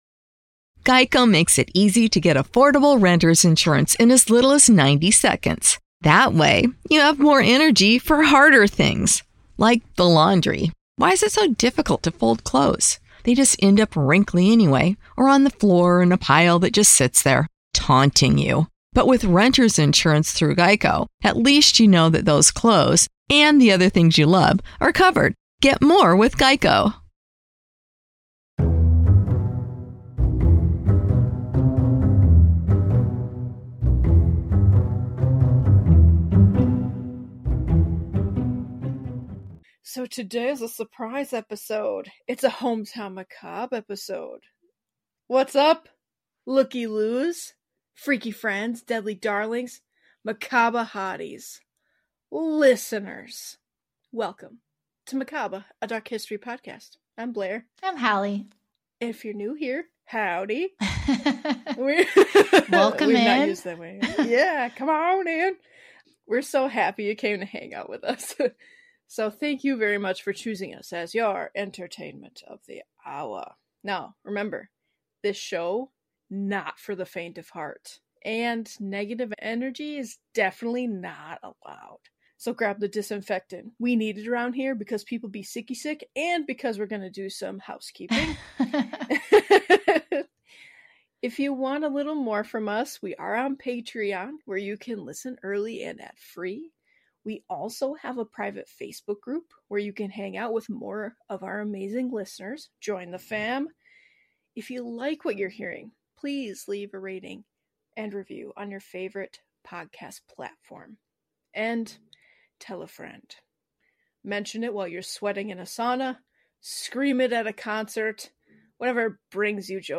Join the ladies as they talk about the big history of a little town.